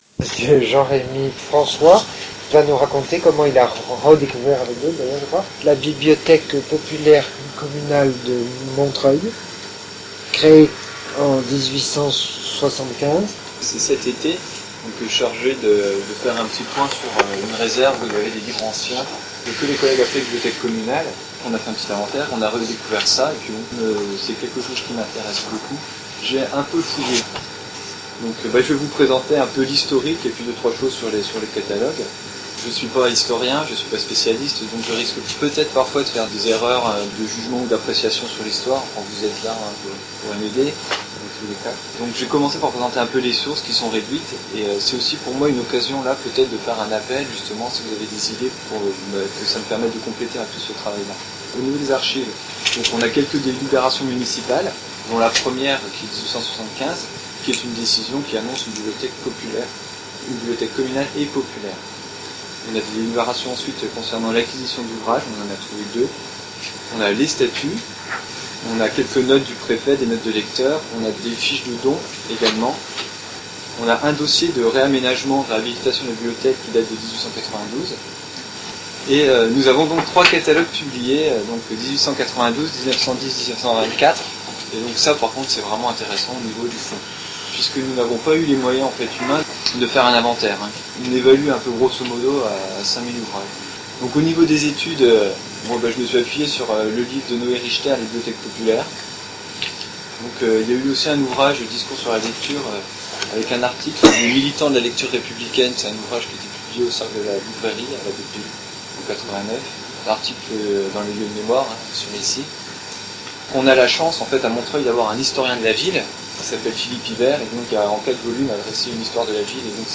Soirée de mars 2008 : La Bibliothèque communale populaire de Montreuil - Bibliothèque des Amis de l'Instruction